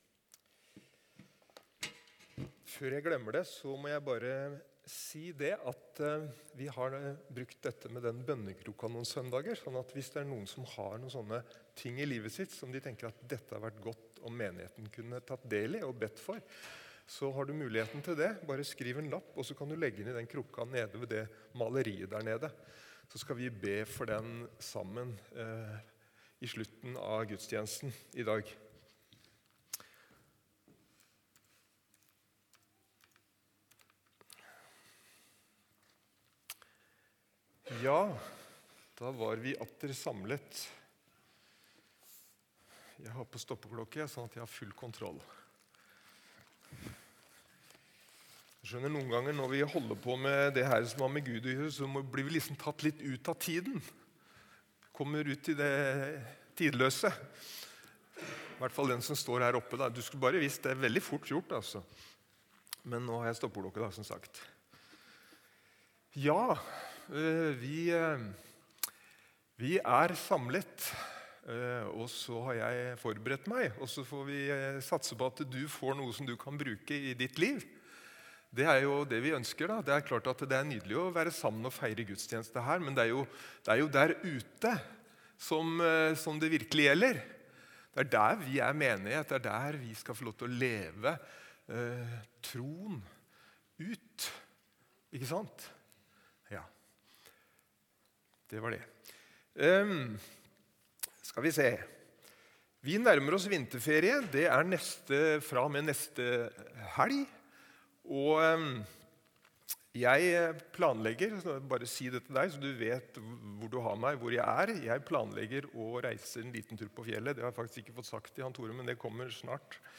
Evangeliehuset Porsgrunn